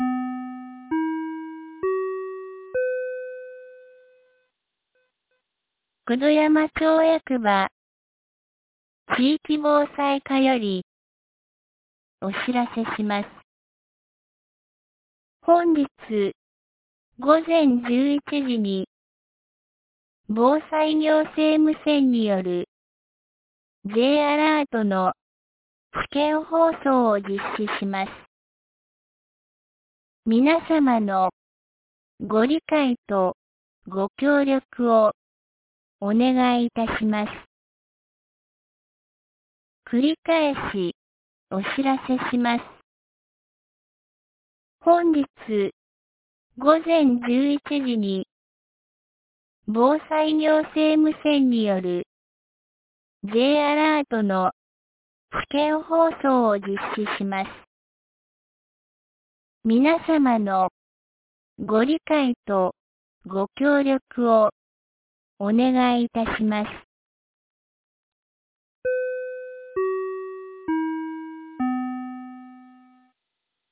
2023年07月12日 10時31分に、九度山町より全地区へ放送がありました。